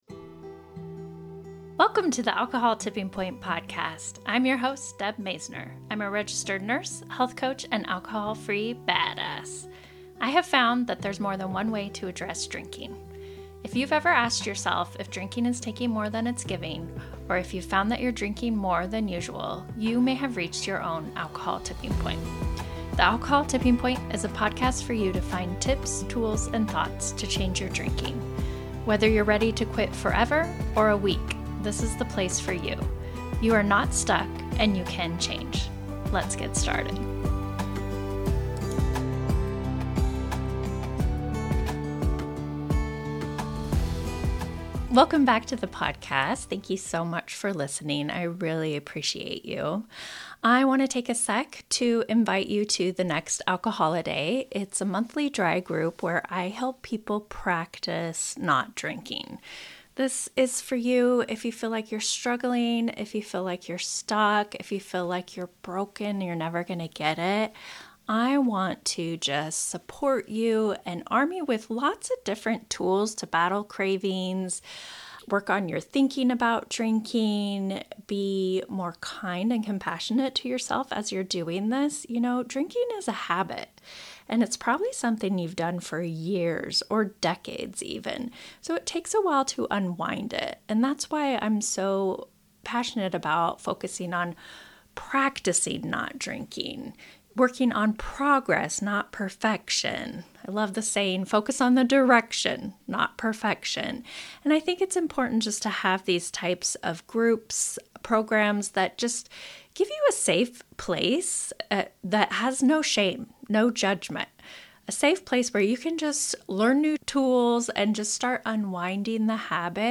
From Sober Curious to Sober Serious: Interview